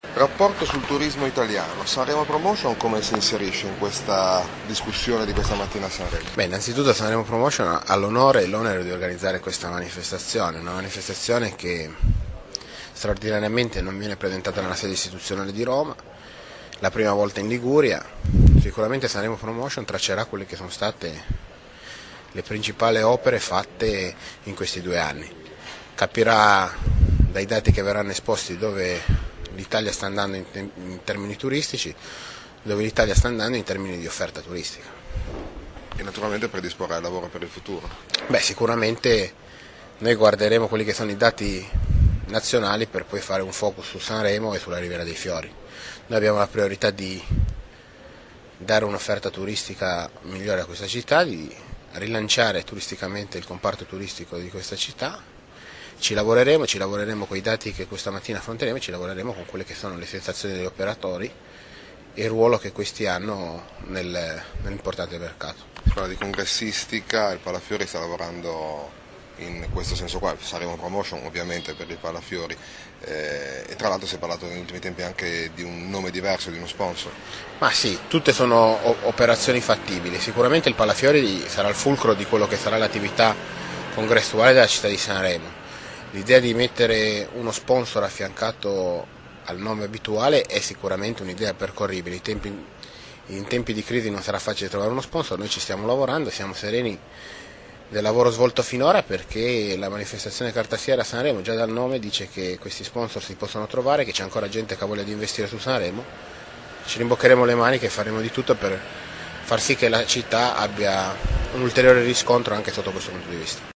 Sanremo: gli interventi alla presentazione del 17° Rapporto del Turismo Italiano